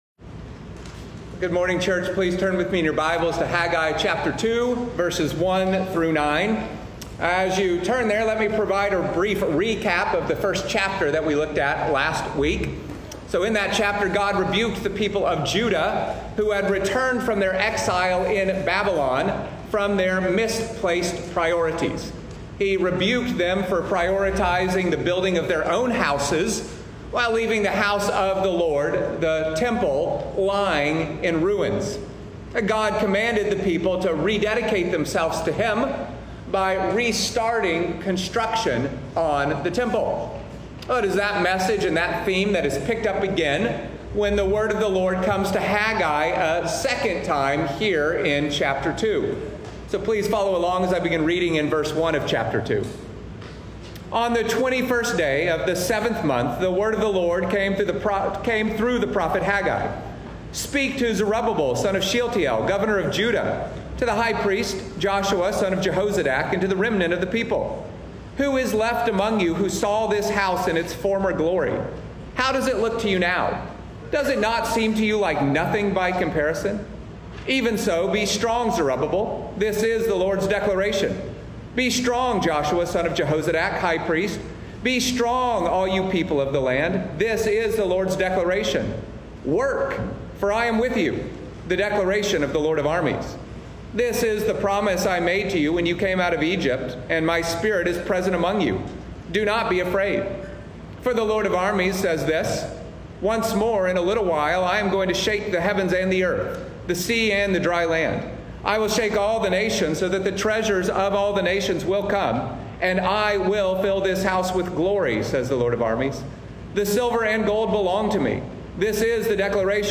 Dealing With Discouragement | Haggai 2:1-9 Immanuel Church Of Fujairah Sermons podcast